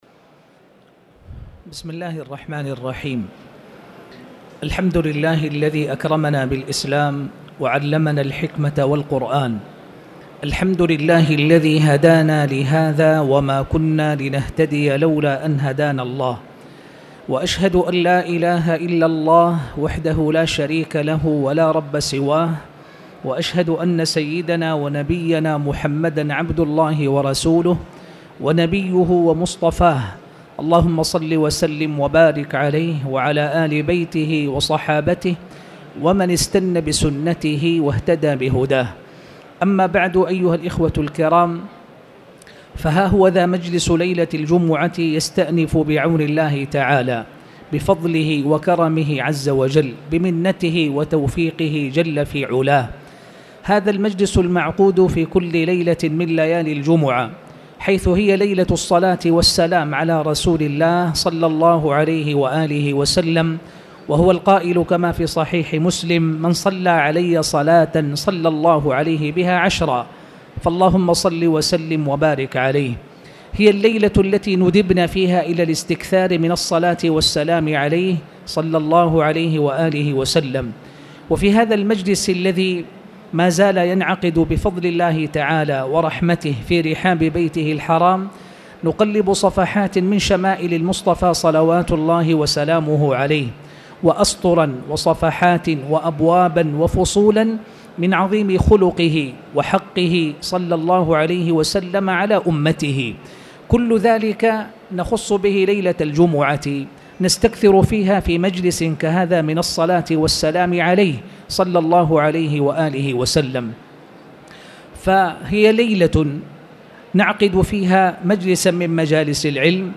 المكان: المسجد الحرام